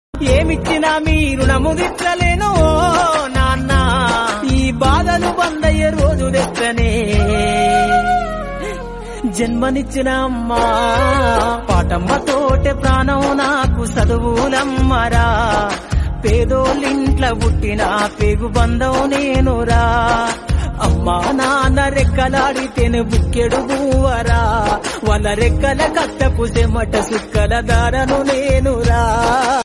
melody ringtone download
emotional ringtone